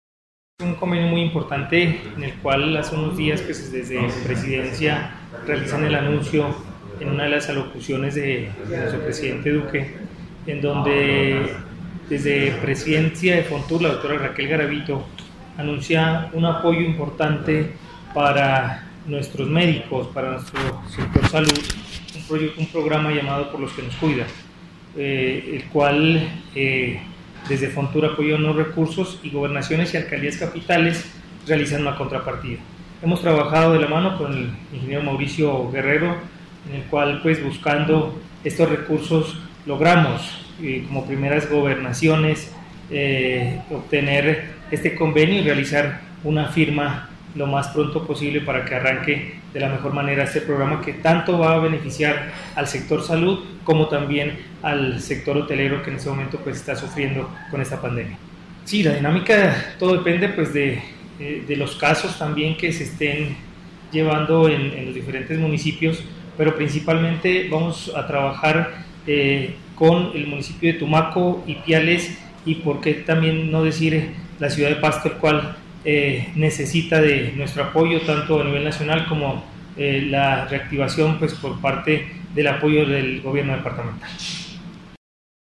Declaración